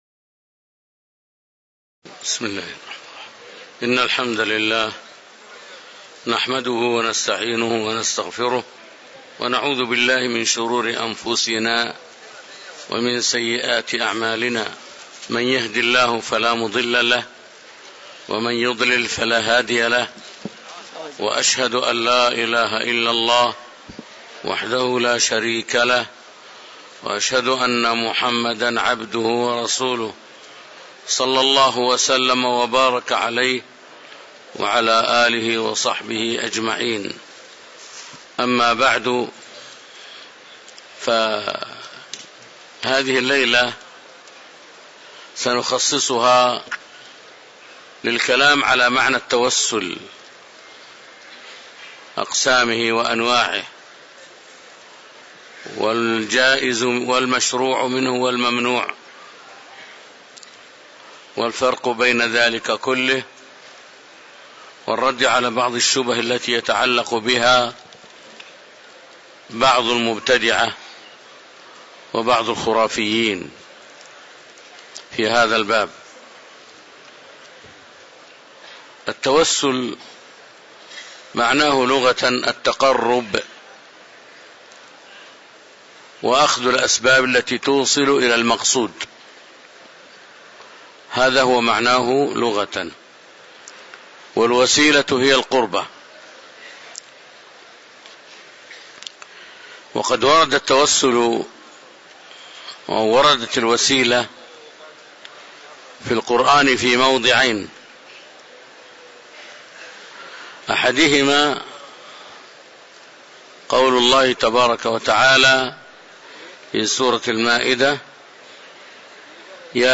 تاريخ النشر ٢٦ ذو الحجة ١٤٤٥ هـ المكان: المسجد النبوي الشيخ